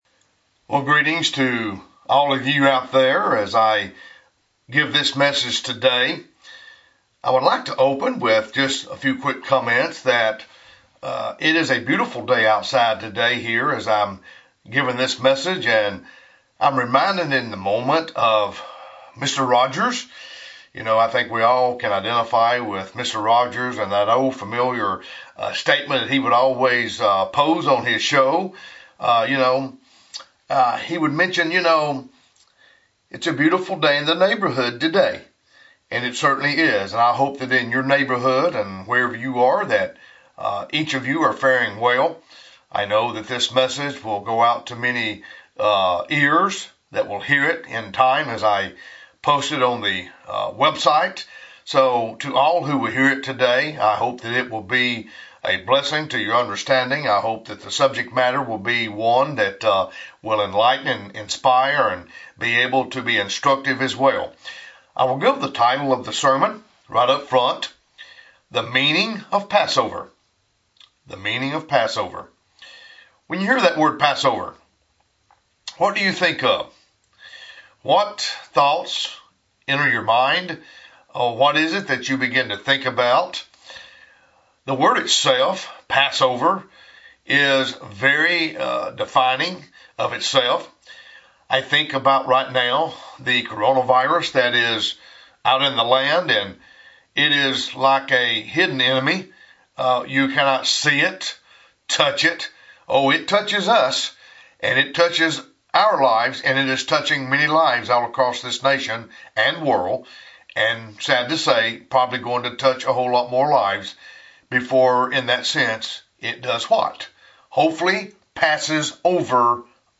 Sermons
Given in Birmingham, AL Huntsville, AL